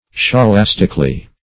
\Scho*las"tic*al*ly\
scholastically.mp3